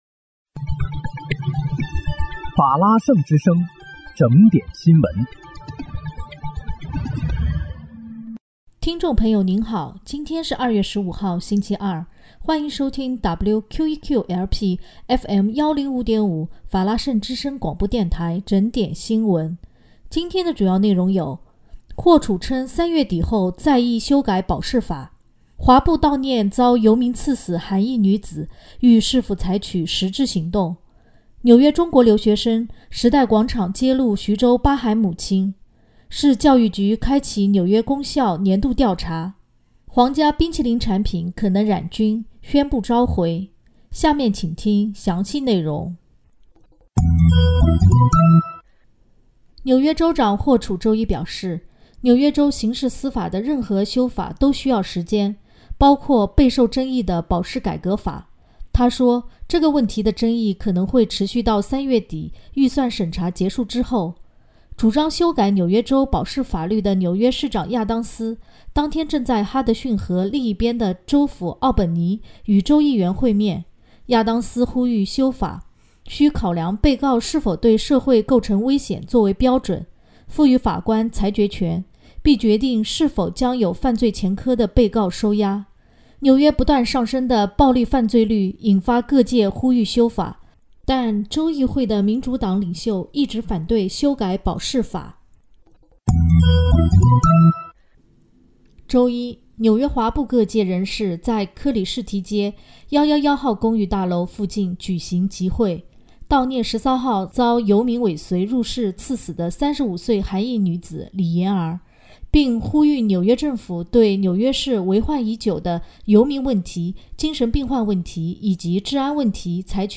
2月15日（星期二）纽约整点新闻